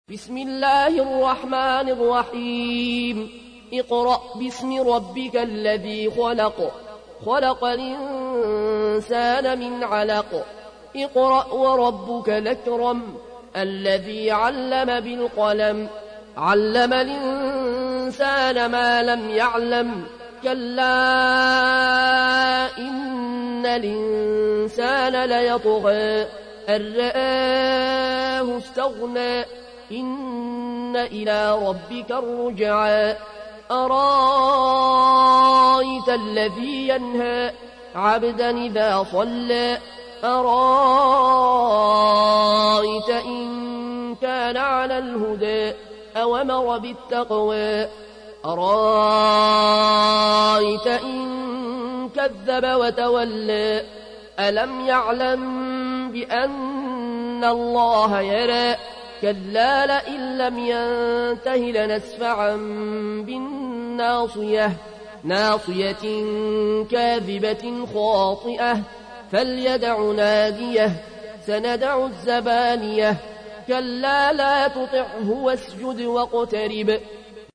تحميل : 96. سورة العلق / القارئ العيون الكوشي / القرآن الكريم / موقع يا حسين